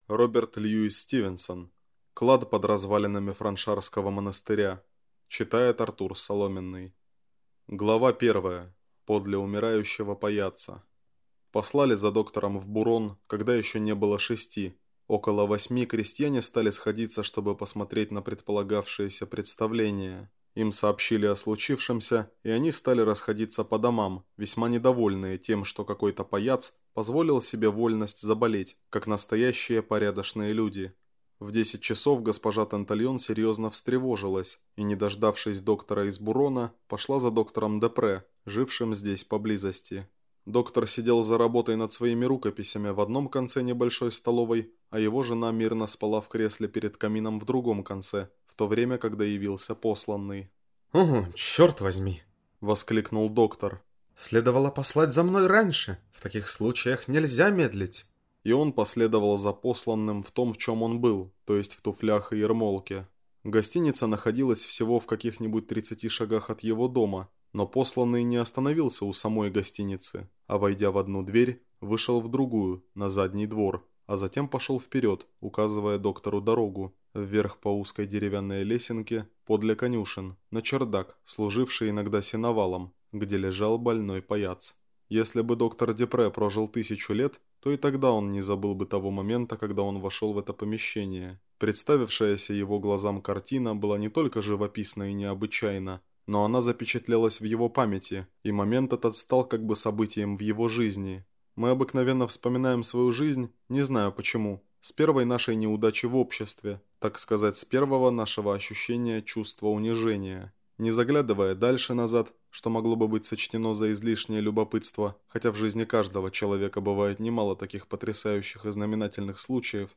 Аудиокнига Клад под развалинами Франшарского монастыря | Библиотека аудиокниг